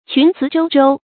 群雌粥粥 qún cí yù yù
群雌粥粥发音